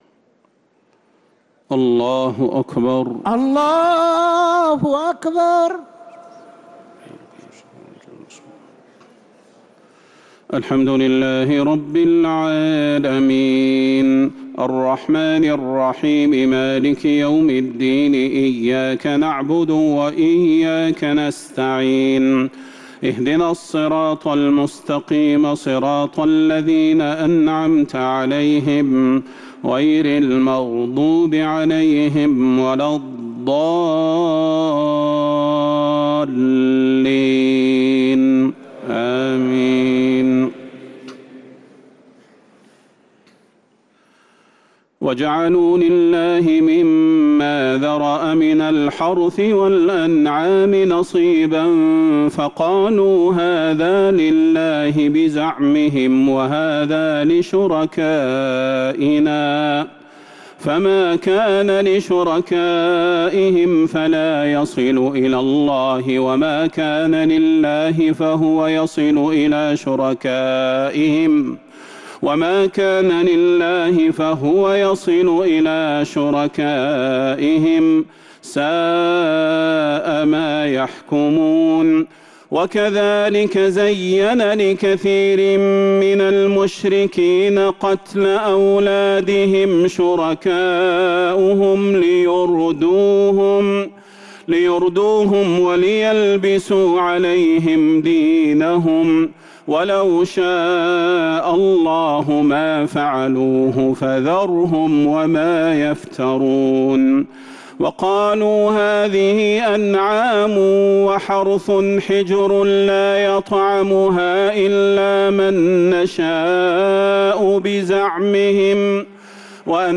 تراويح ليلة 10 رمضان 1447هـ خواتيم سورة الأنعام (136-165) | Taraweeh 10th niqht Ramadan Surat Al-Anaam 1447H > تراويح الحرم النبوي عام 1447 🕌 > التراويح - تلاوات الحرمين